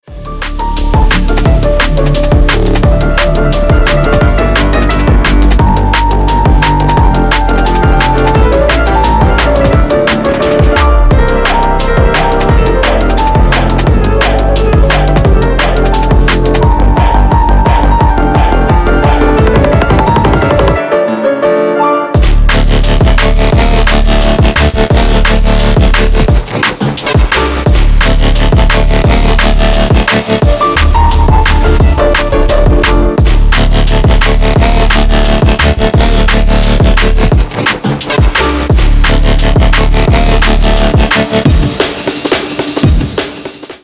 DubStep / DnB рингтоны